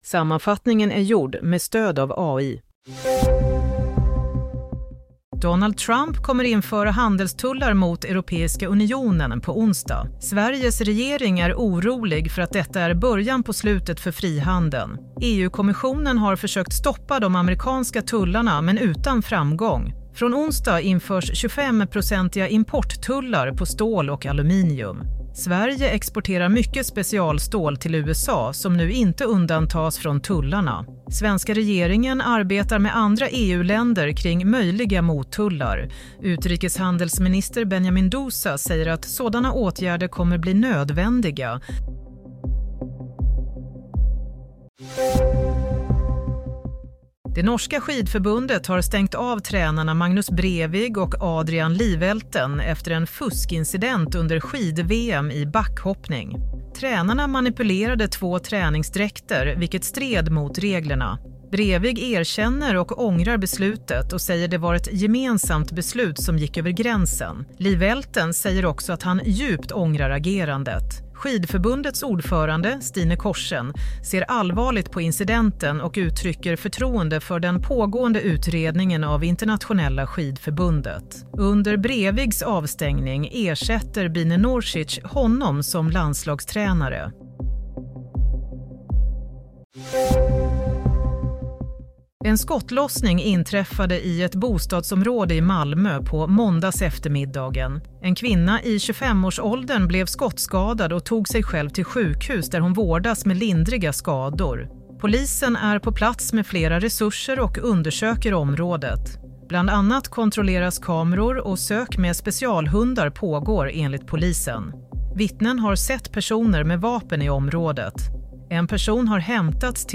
Nyhetssammanfattning - 10 mars 22.30